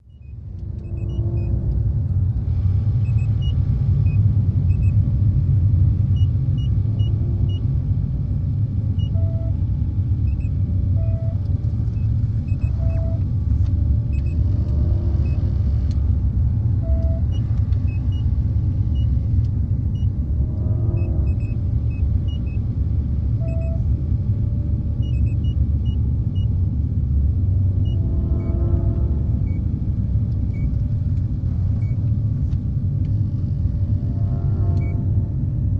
Ship Ambience; Deep Water Hum, Beeps, Buzzes